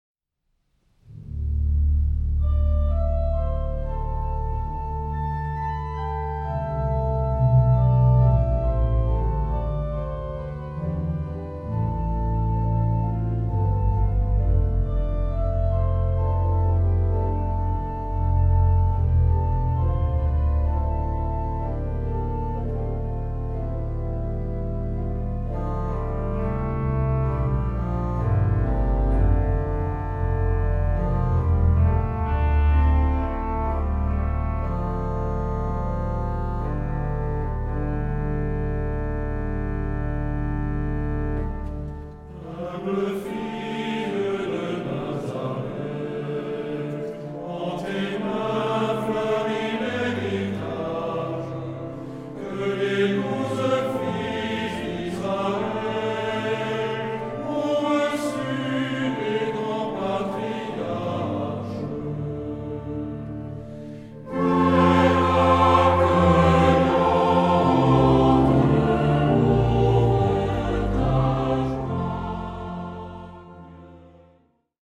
Genre-Style-Forme : Cantique ; Sacré
Caractère de la pièce : binaire
Type de choeur : SATB  (4 voix mixtes OU unisson )
Instruments : Orgue (1)
Tonalité : fa majeur